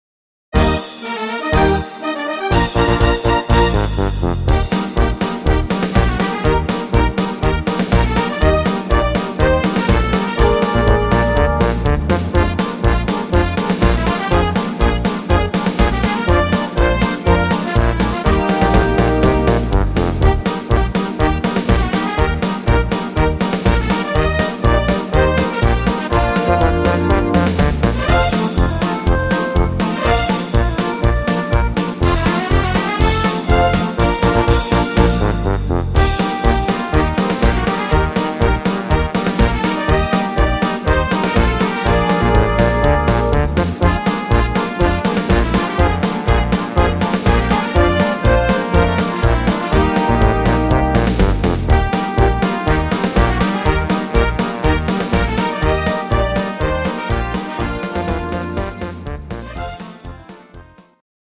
instumental Orchester